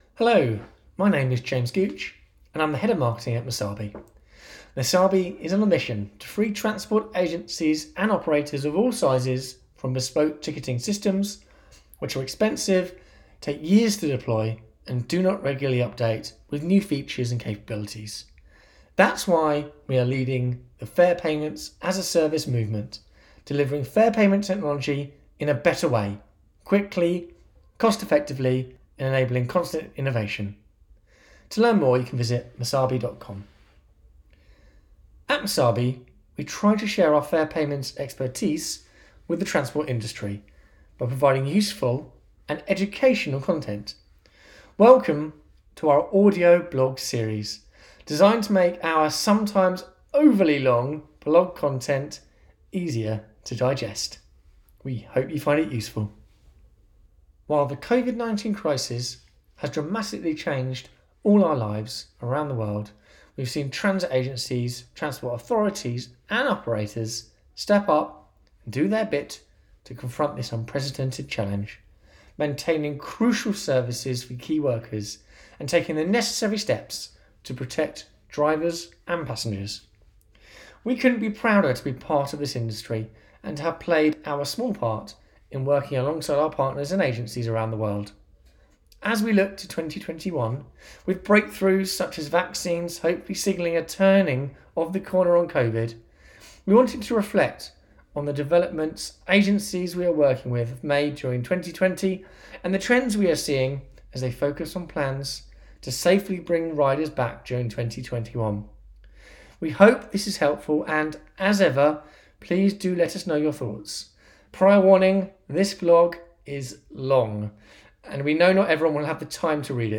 Masabi's Audio Blog - Click here to listen to the audio version of this blog post.